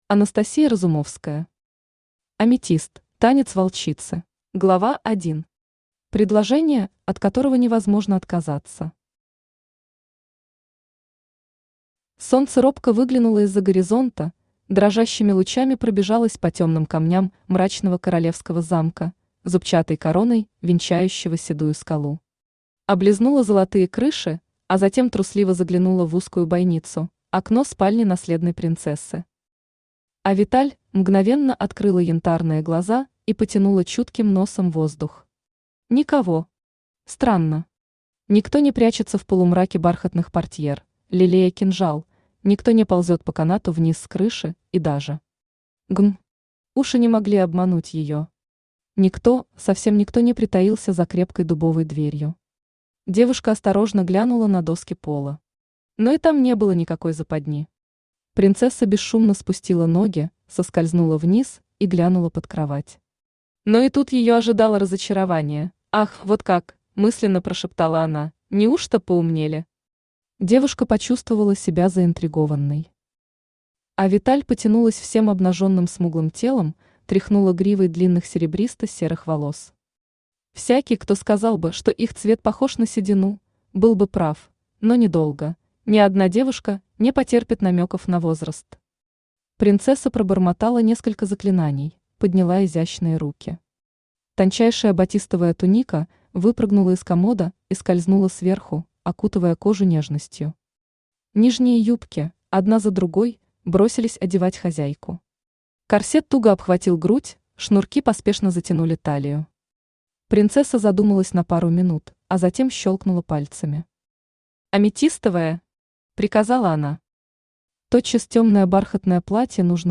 Aудиокнига Аметист: танец волчицы Автор Анастасия Разумовская Читает аудиокнигу Авточтец ЛитРес.